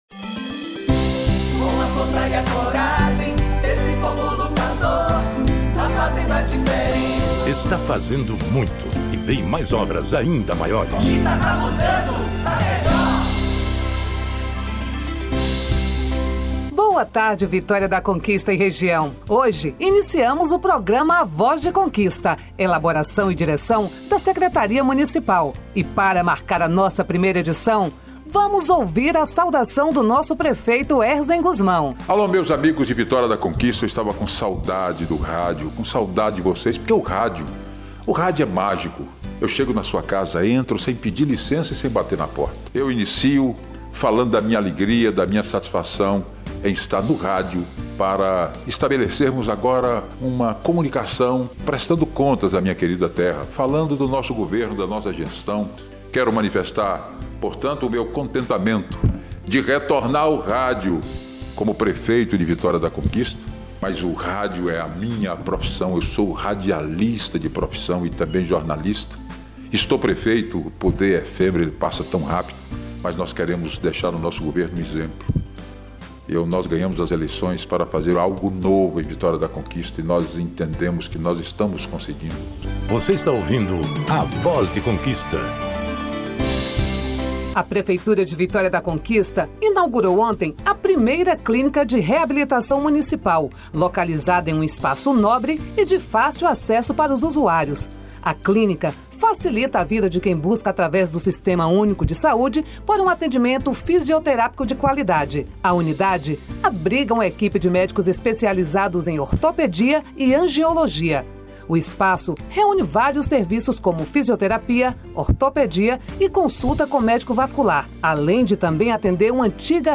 Prefeitura lança o programa de rádio “A Voz de Conquista”; ouça a primeira edição
O programa é transmitido por importantes emissoras de rádio do município, sempre às sextas-feiras, às 11:45h.